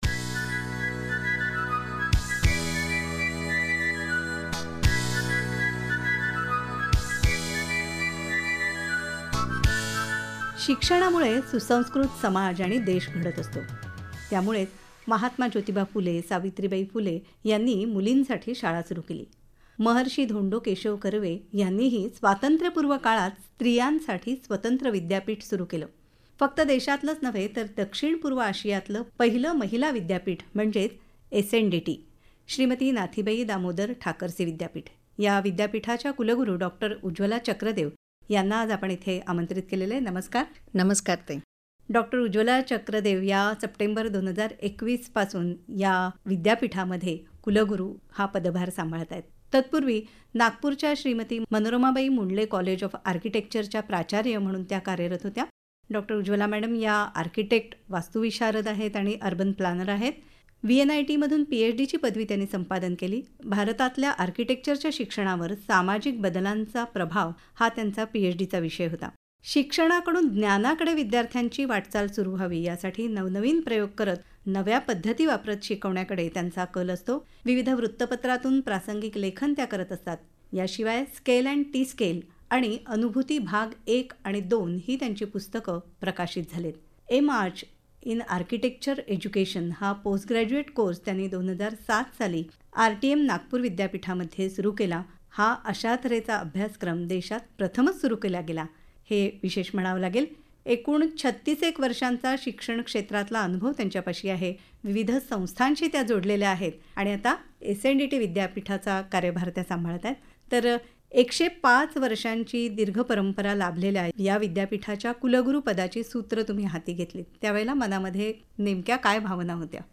Interview in AIR Mumbai